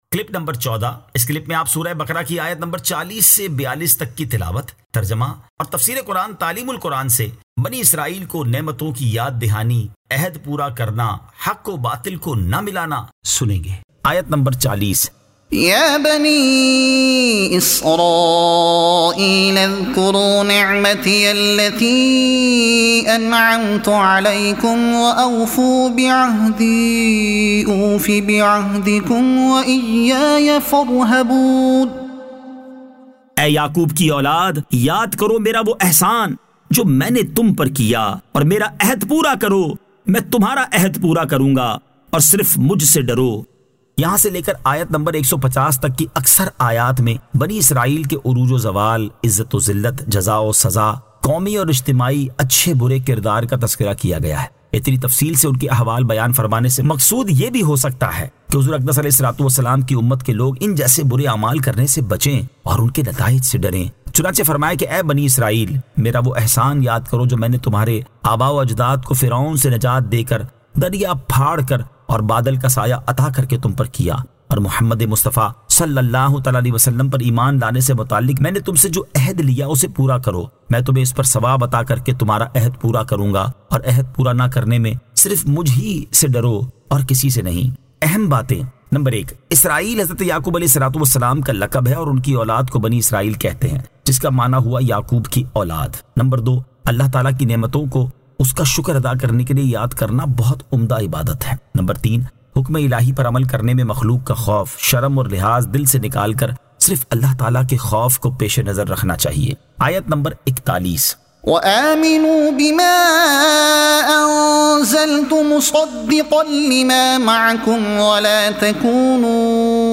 Surah Al-Baqara Ayat 40 To 42 Tilawat , Tarjuma , Tafseer e Taleem ul Quran